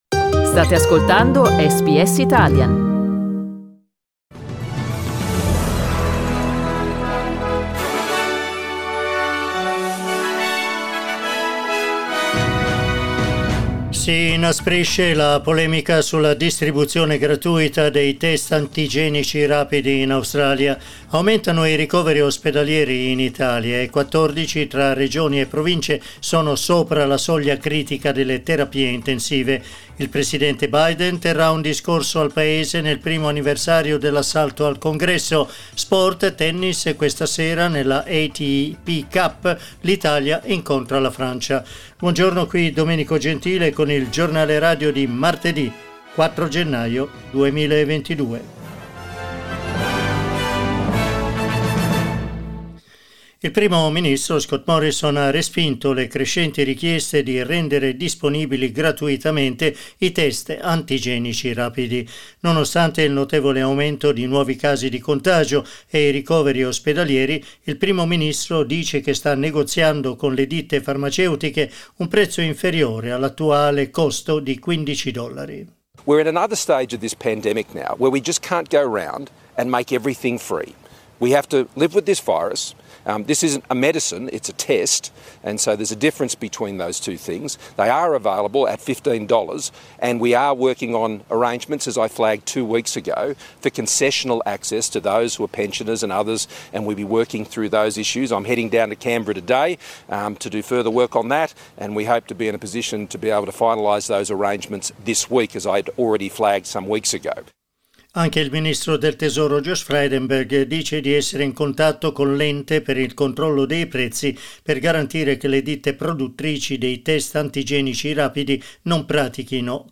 Giornale radio martedì 4 gennaio 2022
Il notiziario di SBS in italiano.